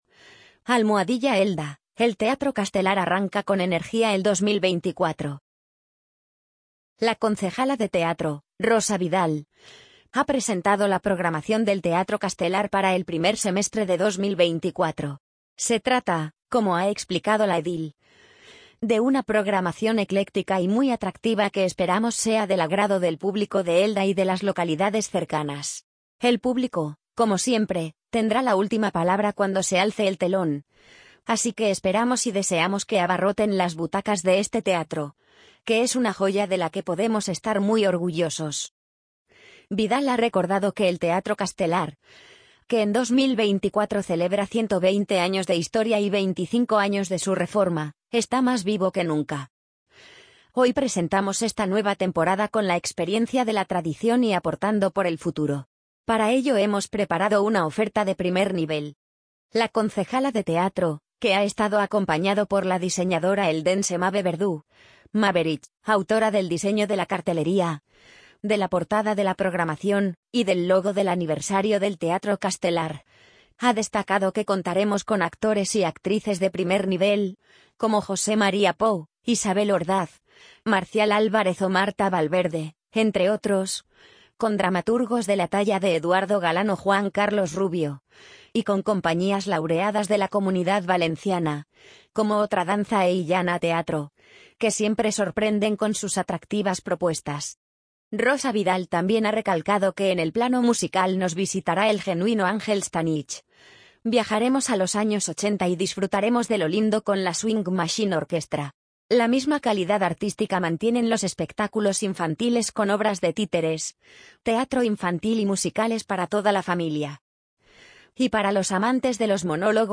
amazon_polly_70057.mp3